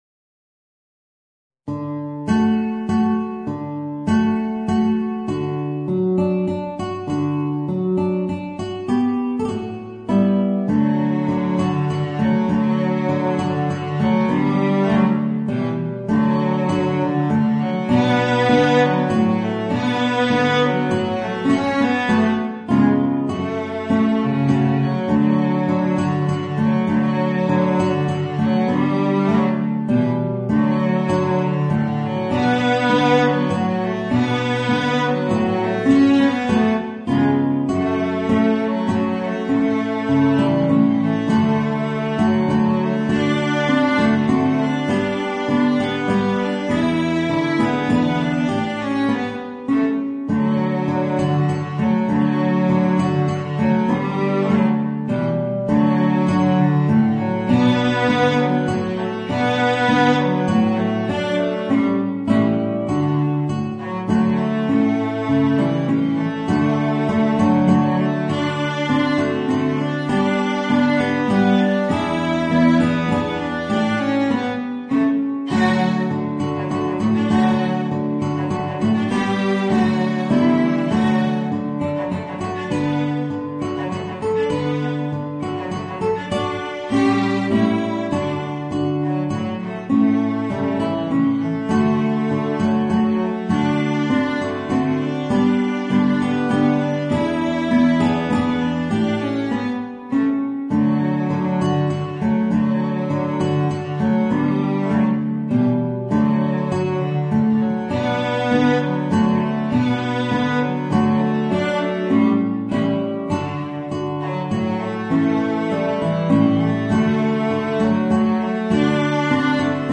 Voicing: Guitar and Violoncello